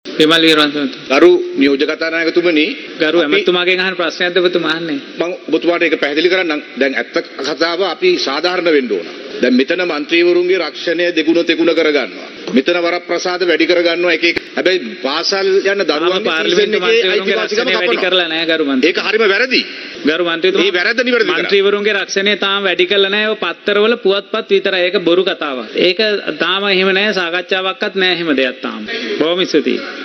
අද පාර්ලිමේන්තුවේදී අදහස් දක්වමින් ඔහු පැවසුවේ මෙම ක්‍රියාවලිය අසාධාරණ බවයි.